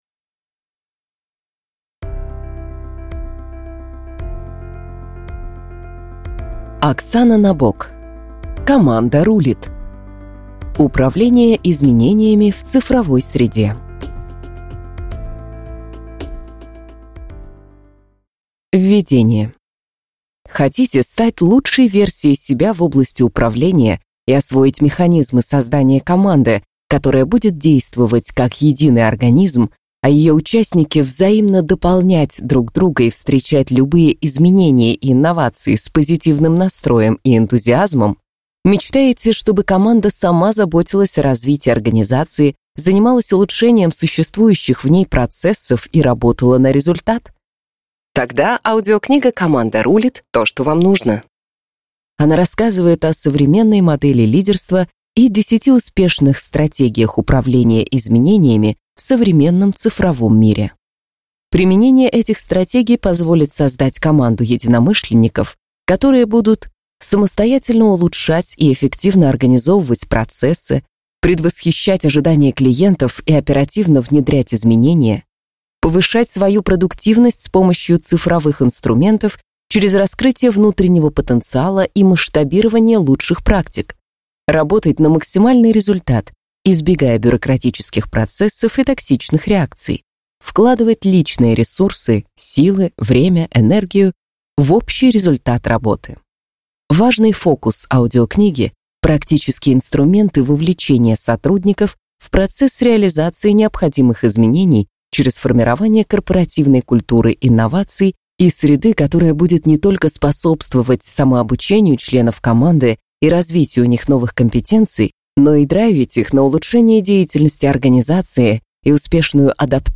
Аудиокнига Команда рулит. Управление изменениями в цифровой среде | Библиотека аудиокниг